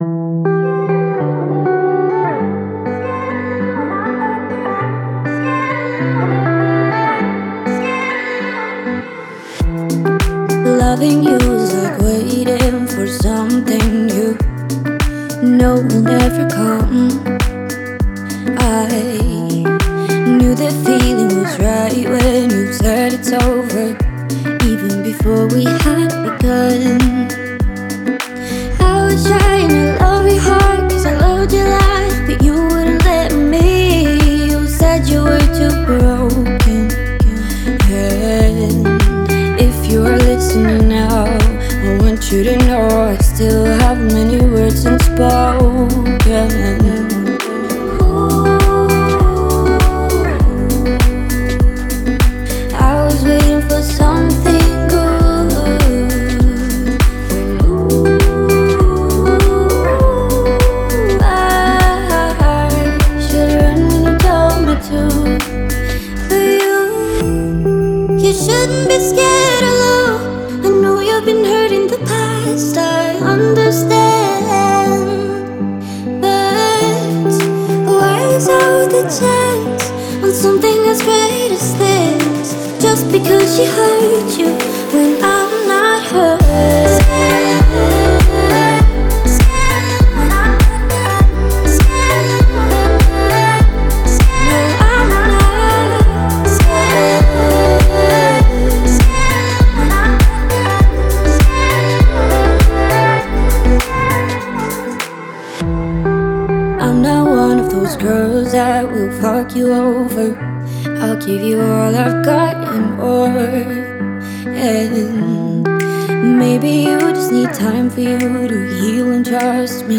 электронная поп-композиция